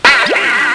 1 channel
zbonk.mp3